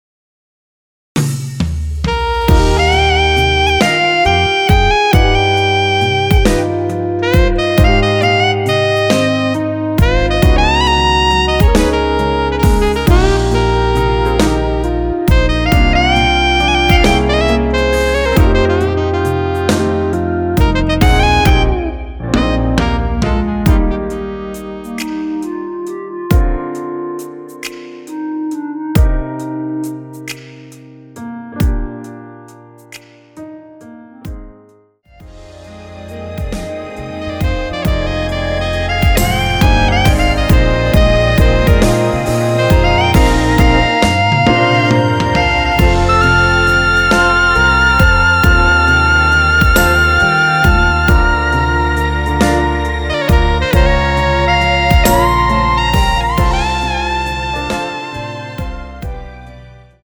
원키에서(+4)올린 멜로디 포함된 MR입니다.(미리듣기 확인)
Eb
앞부분30초, 뒷부분30초씩 편집해서 올려 드리고 있습니다.
중간에 음이 끈어지고 다시 나오는 이유는